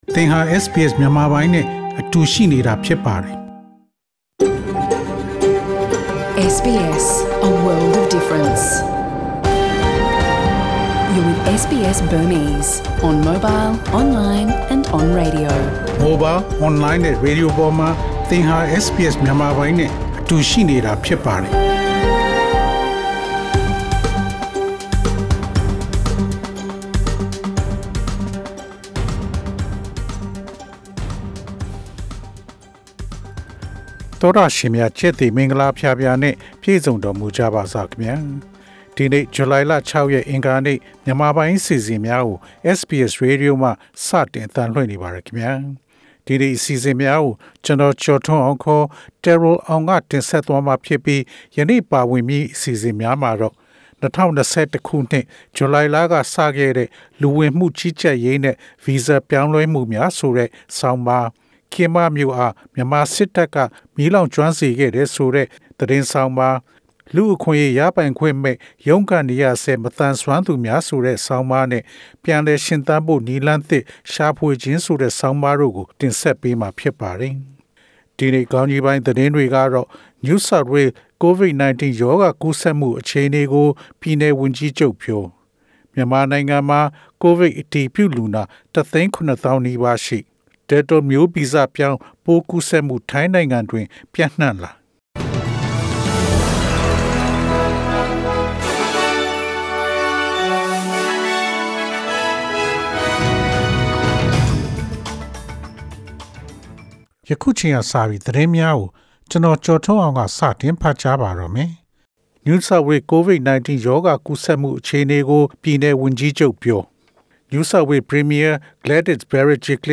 SBS မြန်မာပိုင်း အစီအစဉ် ပေါ့ကတ်စ် သတင်းများ။